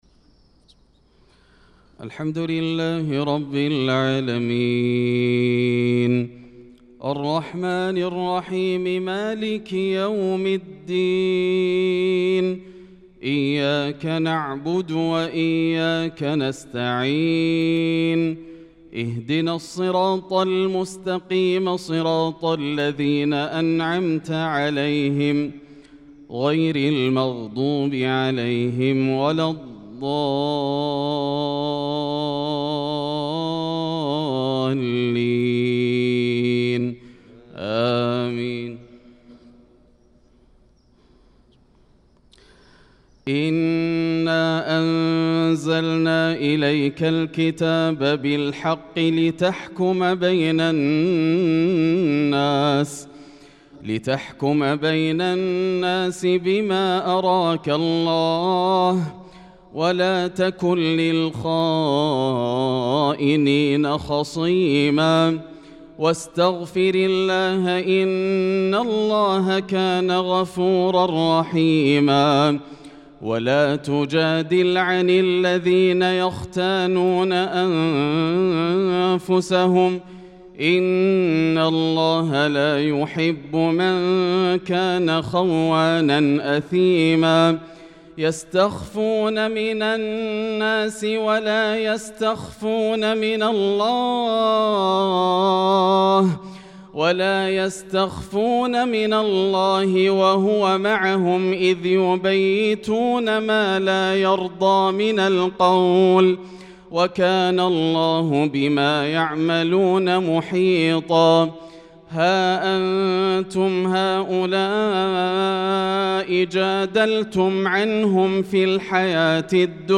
صلاة الفجر للقارئ ياسر الدوسري 4 ذو القعدة 1445 هـ
تِلَاوَات الْحَرَمَيْن .